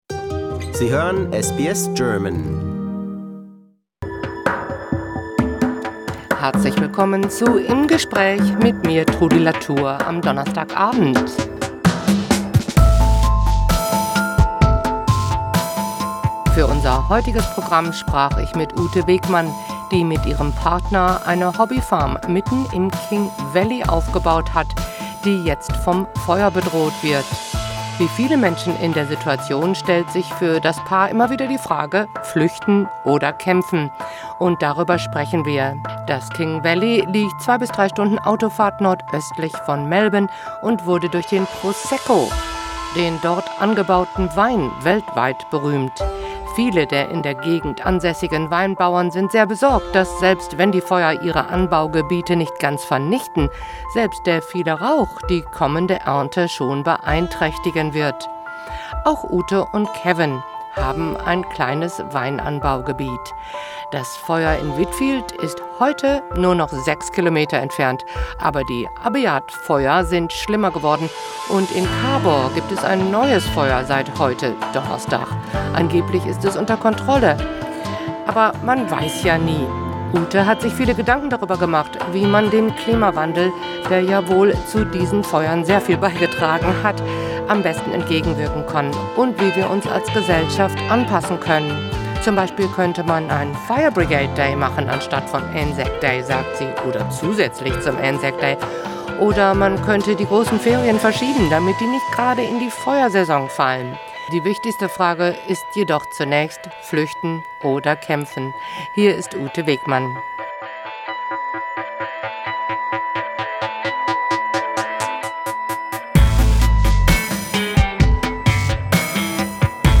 Im Gespräch: Die Angst vor dem Anklopfen der Fire App